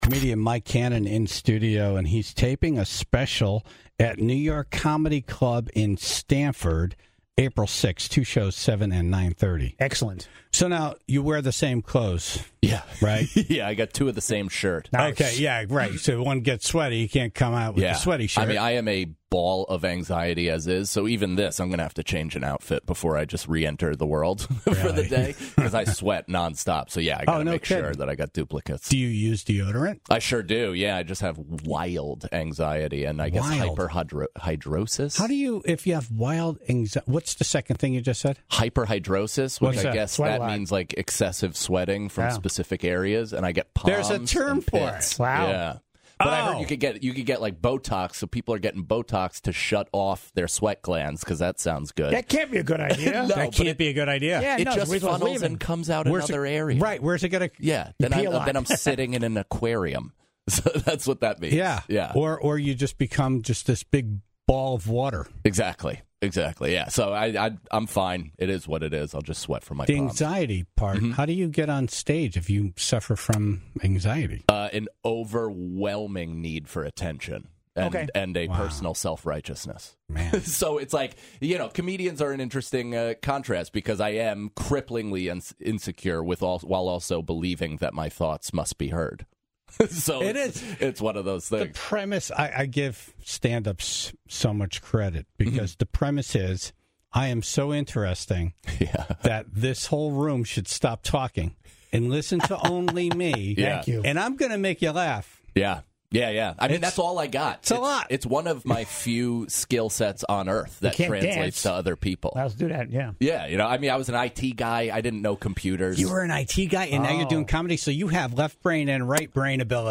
The Tribe called in with their best bluffs with work and personal lives.